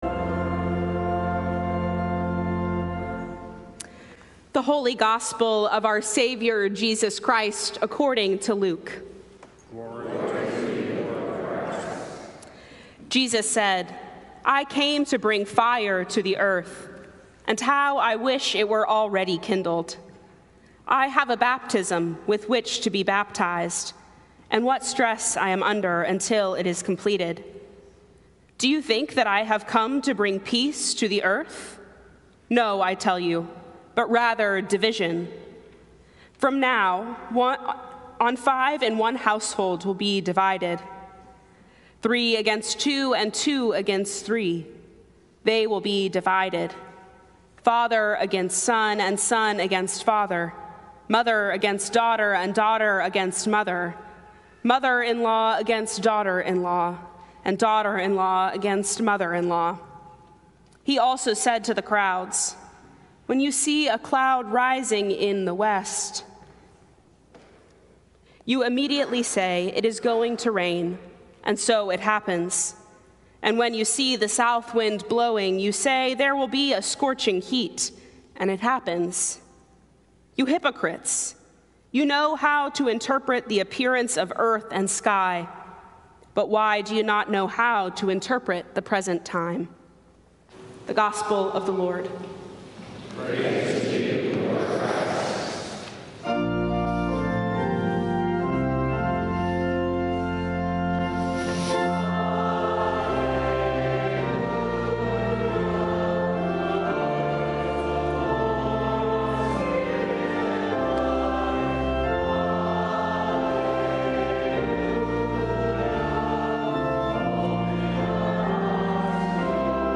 Sermons
St. Columba's in Washington, D.C. Running in the Jesus Invitational